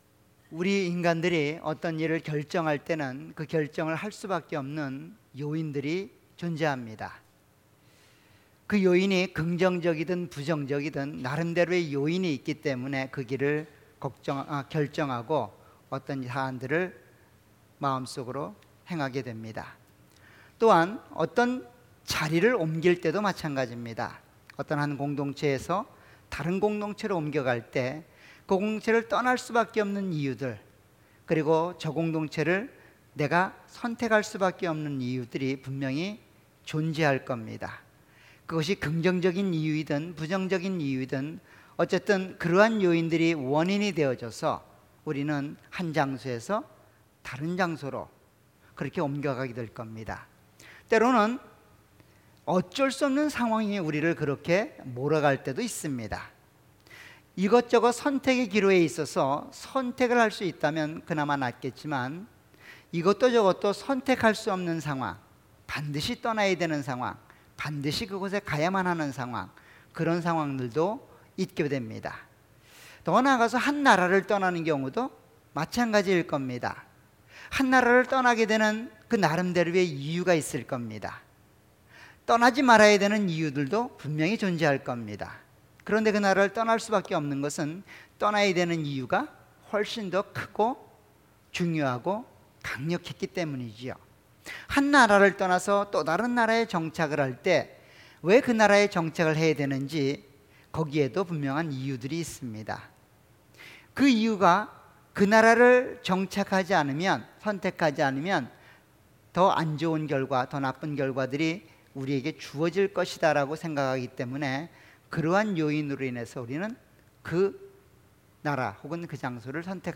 All Sermons
Series: 수요예배.Wednesday